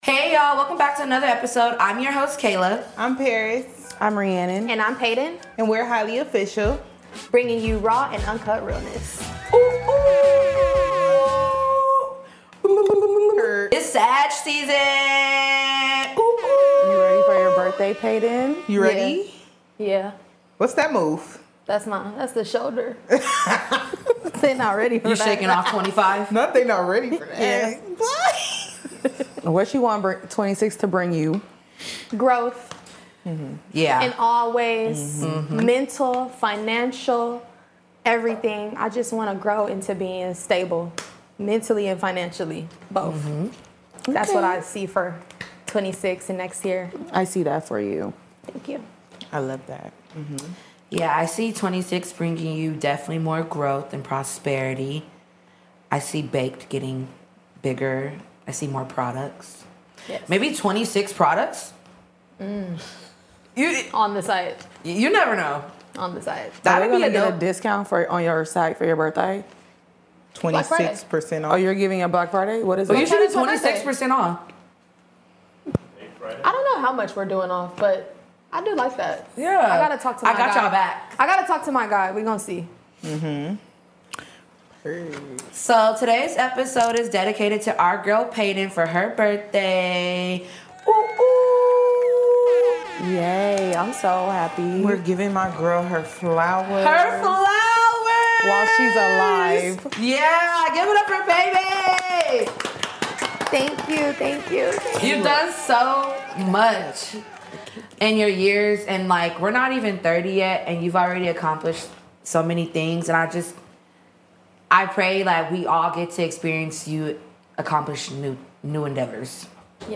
Highly Official Podcast will be bringing you raw and uncut realness from four stoner best friends in Los Angeles.